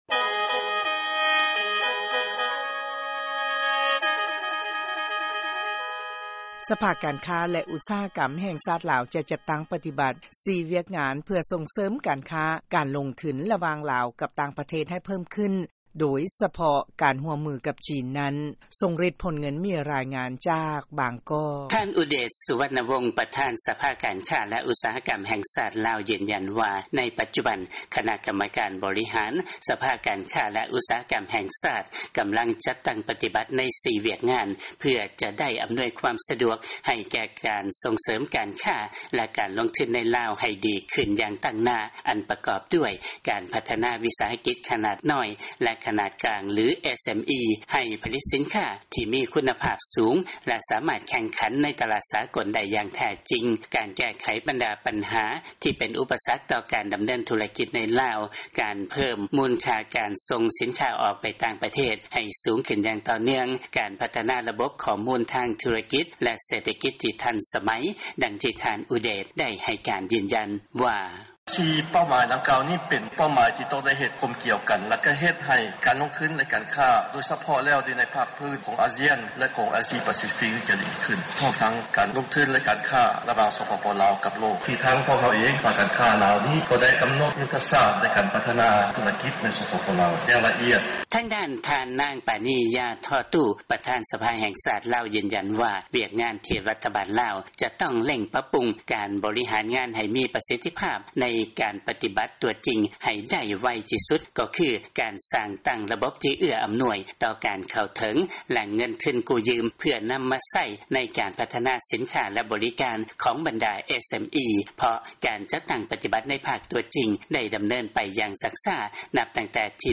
ເຊີນຟັງລາຍງານ ສະພາການຄ້າ ແລະ ອຸດສາຫະກຳແຫ່ງຊາດ ລາວ ຈະປະຕິບັດ 4 ວຽກງານ ເພື່ອສົ່ງເສີມການຄ້າ-ການລົງທຶນ ກັບຕ່າງປະເທດ